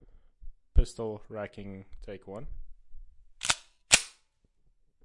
贝雷塔9毫米口径锁扣较慢
描述：与快速重装相同，只是慢一些
标签： 9毫米 手枪 枪支 现场 录像奥尔丁 压簧 Berretta 刷新
声道立体声